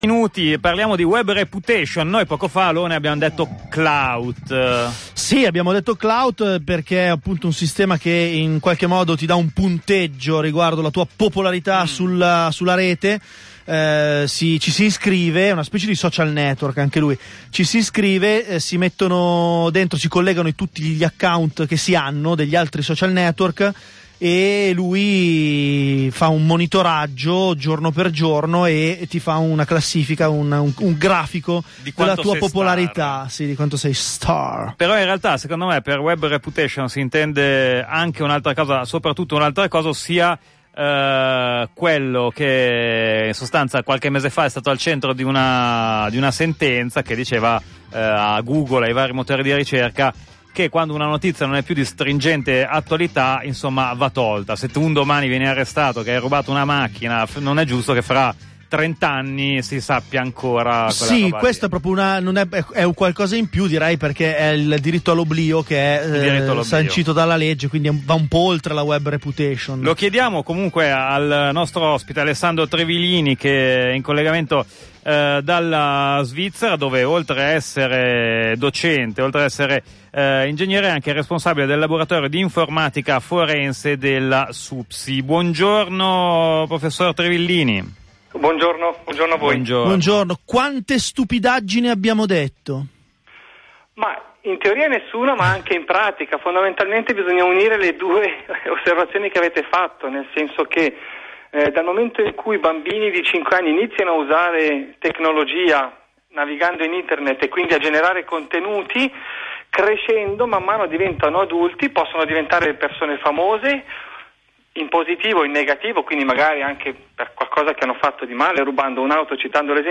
intervistawebreputation.mp3